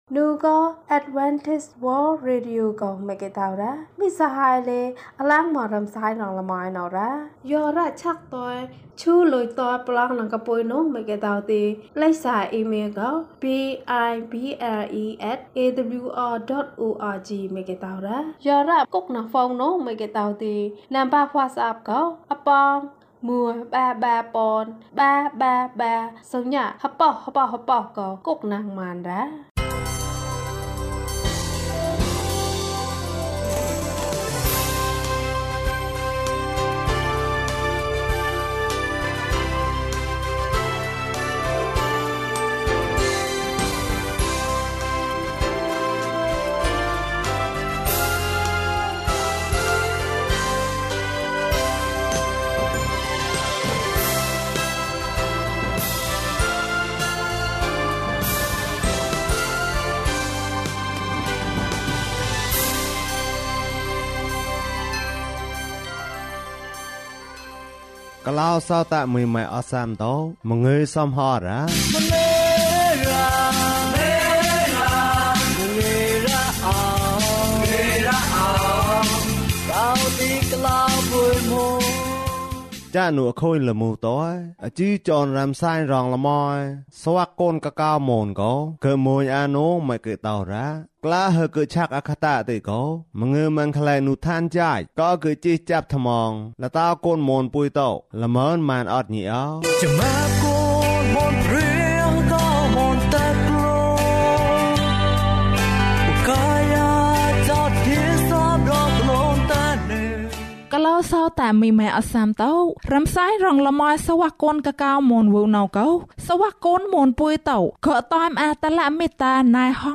အပြစ်အတွက် ယေရှုအသေခံ၊ ကျန်းမာခြင်းအကြောင်းအရာ။ ဓမ္မသီချင်း။ တရားဒေသနာ။